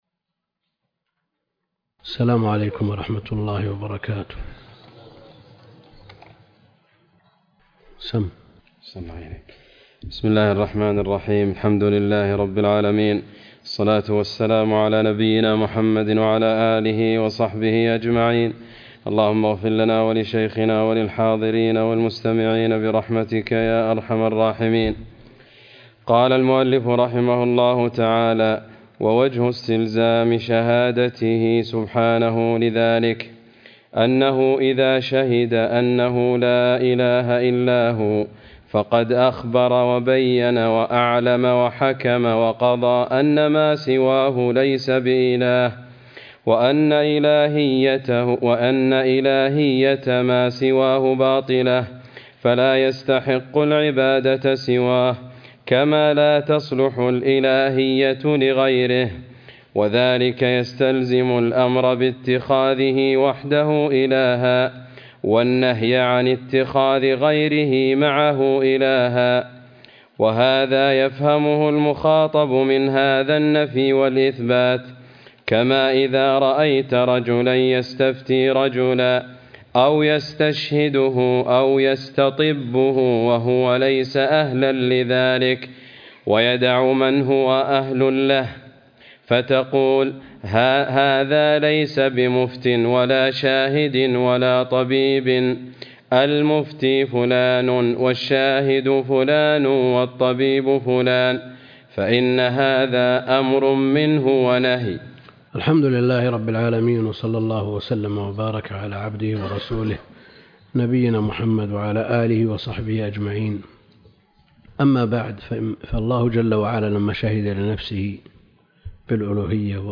عنوان المادة الدرس (6) شرح العقيدة الطحاوية تاريخ التحميل السبت 21 يناير 2023 مـ حجم المادة 34.14 ميجا بايت عدد الزيارات 236 زيارة عدد مرات الحفظ 116 مرة إستماع المادة حفظ المادة اضف تعليقك أرسل لصديق